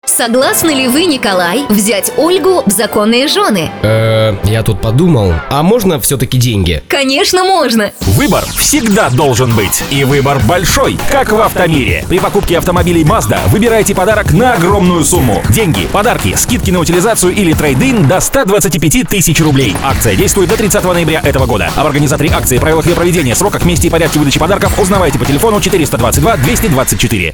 ролик для автосалона "МAZDA"